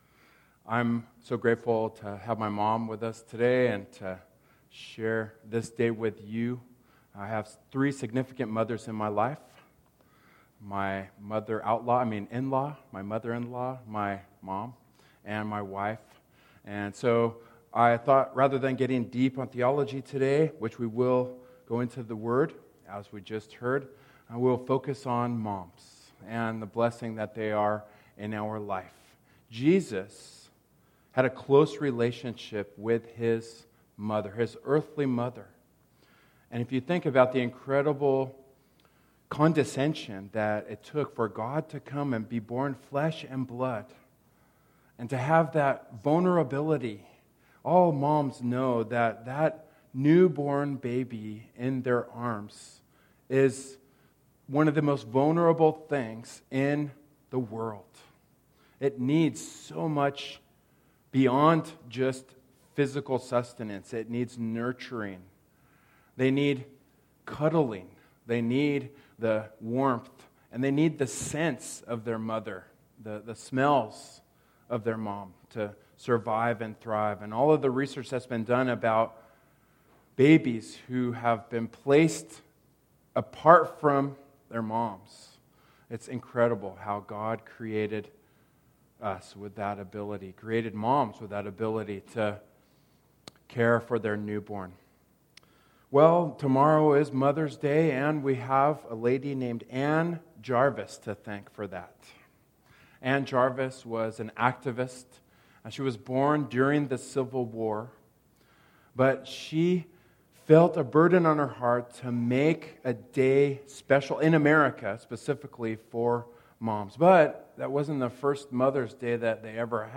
Service Type: Worship Service Topics: Mothers